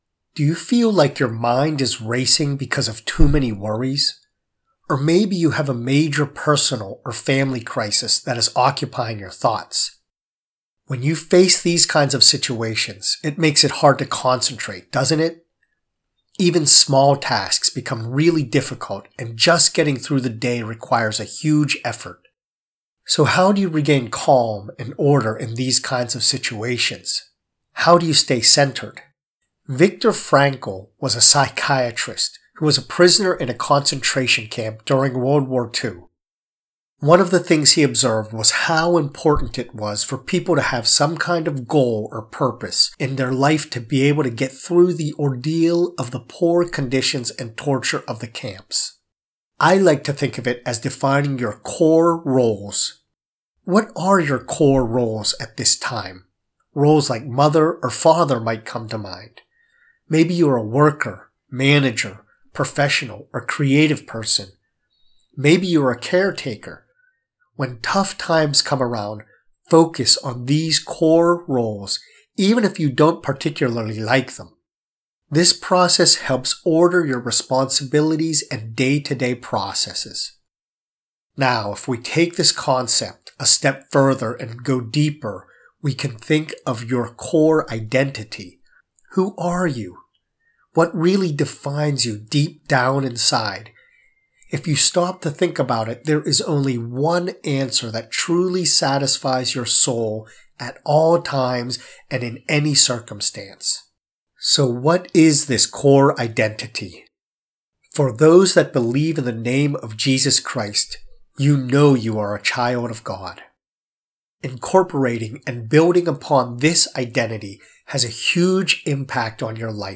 prayer-to-stay-centered.mp3